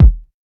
Kick (62).wav